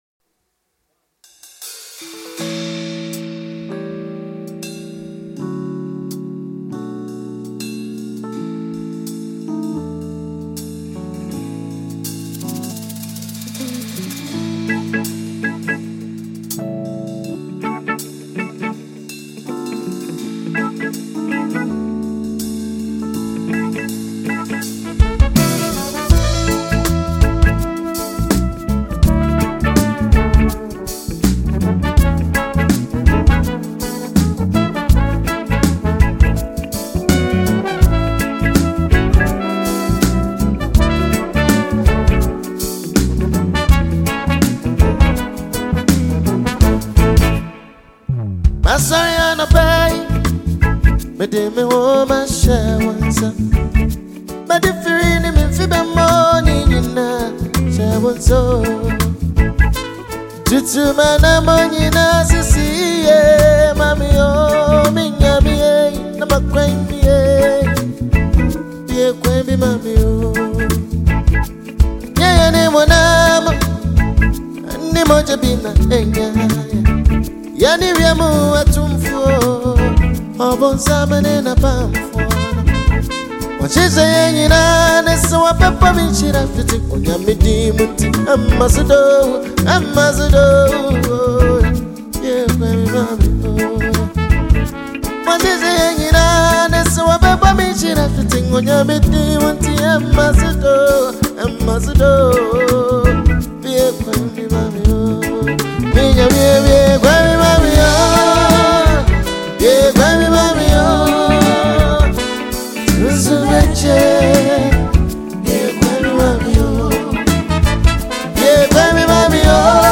Highly-rated Ghanaian musician and songwriter